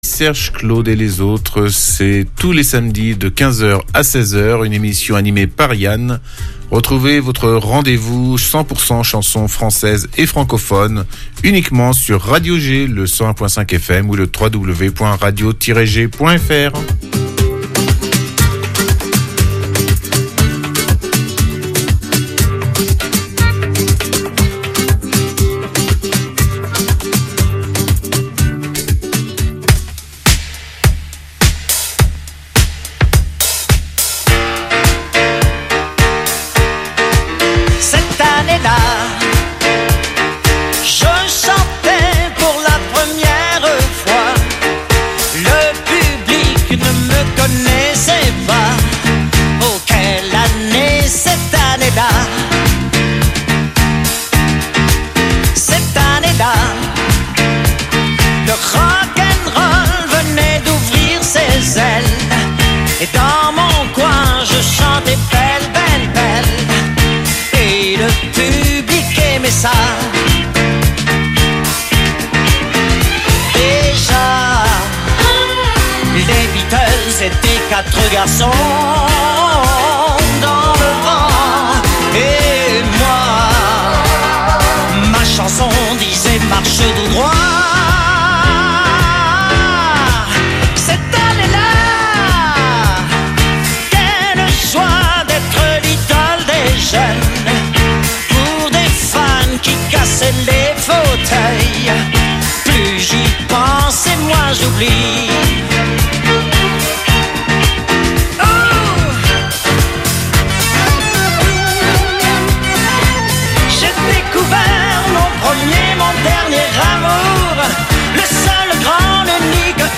chansons françaises